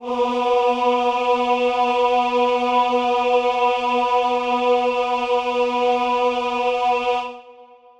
B3.wav